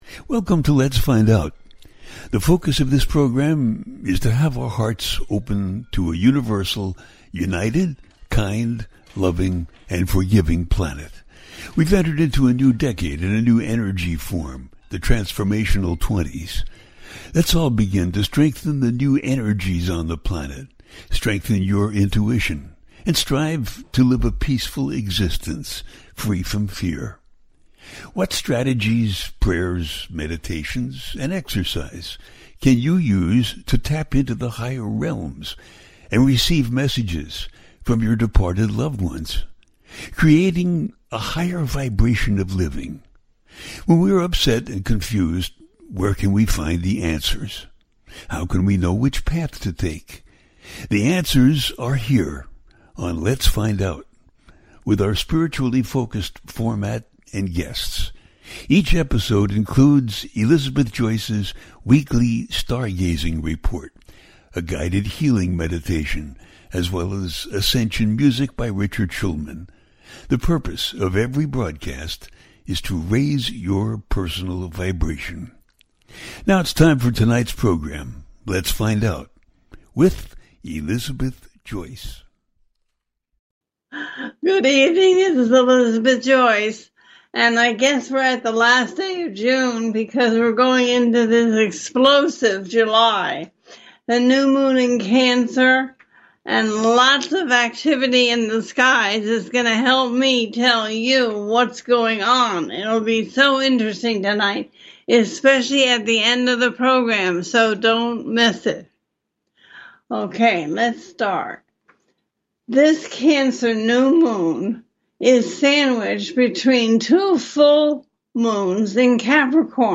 A teaching show.
The listener can call in to ask a question on the air.
Each show ends with a guided meditation.